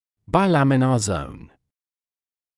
[baɪ’læmɪnə zəun][бай’лэминэ зоун]биламинарная зона